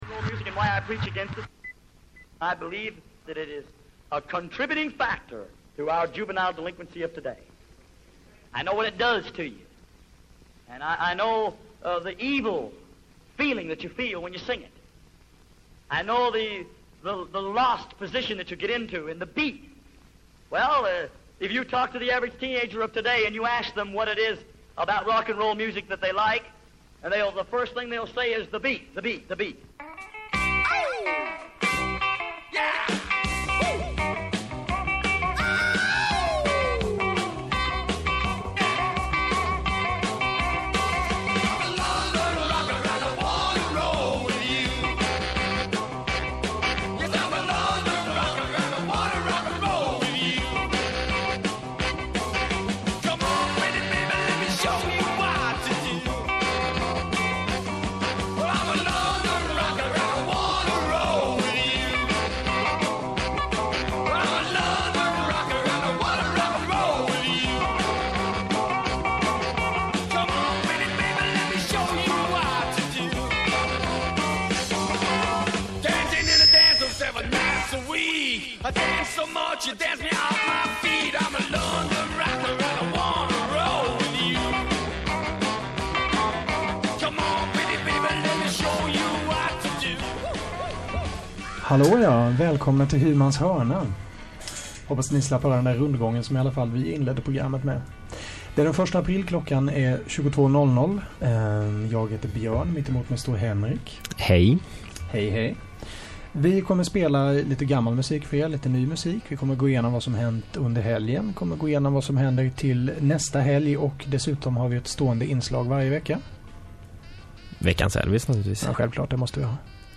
Hymans H�rna handlar om rock n' roll, hela v�gen fr�n country och blues fram till punk och h�rdrock. Det blir sprillans nytt s�v�l som knastrande gammalt, varvat med intervjuer och reportage fr�n G�teborgs musikv�rld.